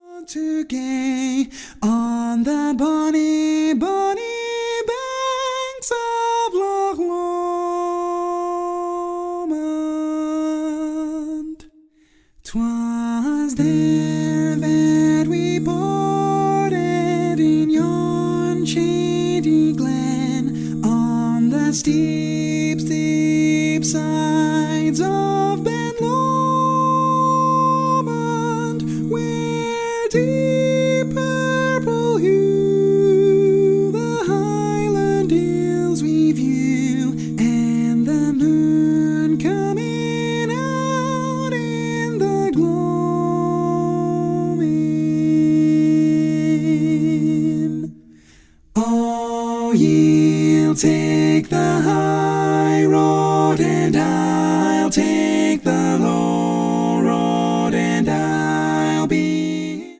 Female
4parts